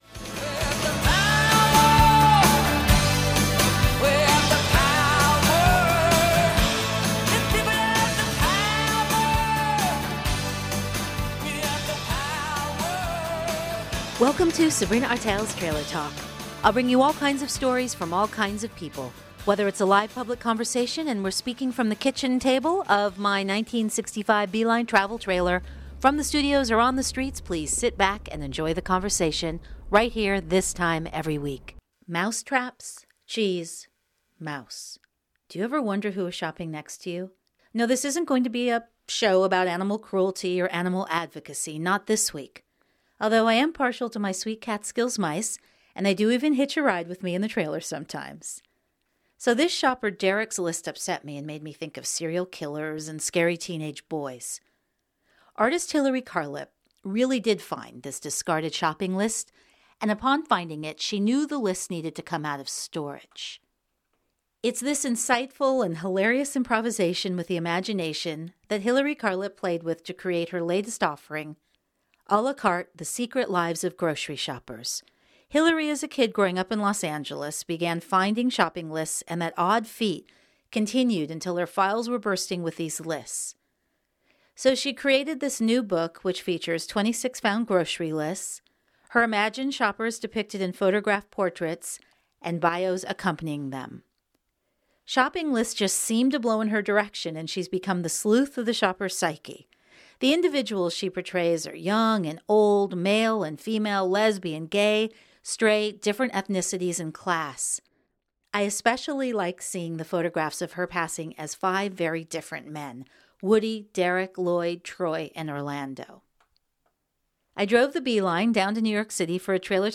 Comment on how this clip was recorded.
And there's a radio interview I did on TRAILER TALK, a KILLER show that's taped in (and sometimes airs live from) A VINTAGE TRAILER!